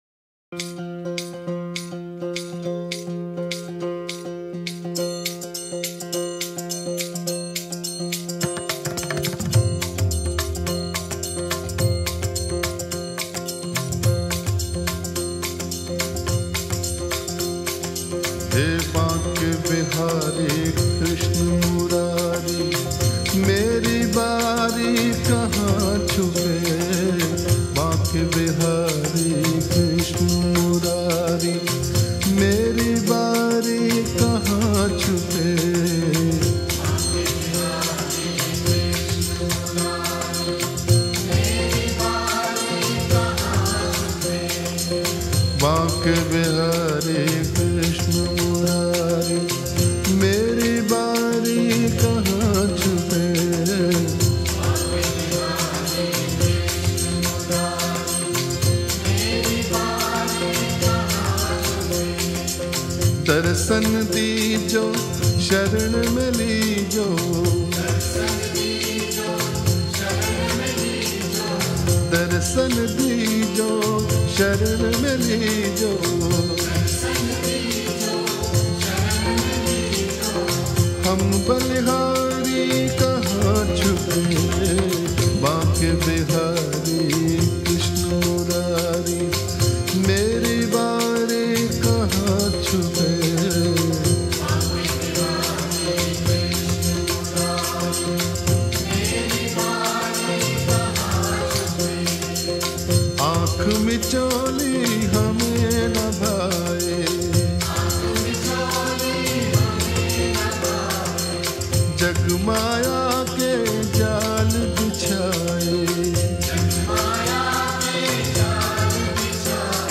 Hindi Devotional Song
Krishna Bhajan